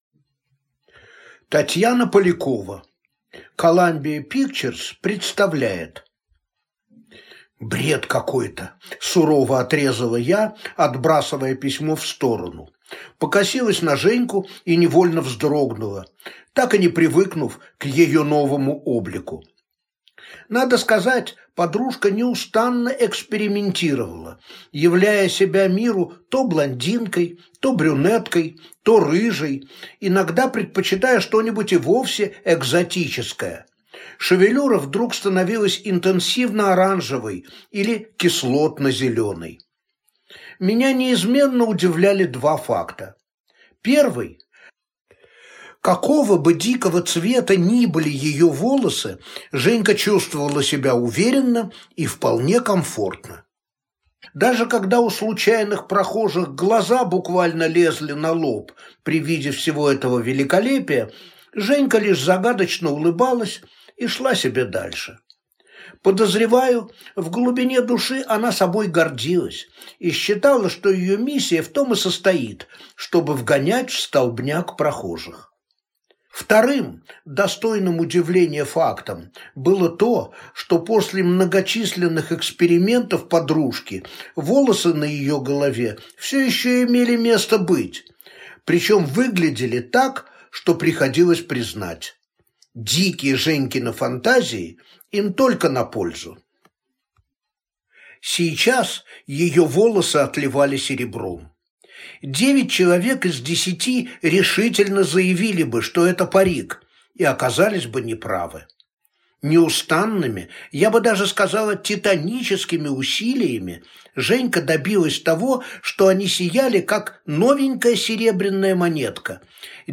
Аудиокнига «Коламбия пикчерз» представляет | Библиотека аудиокниг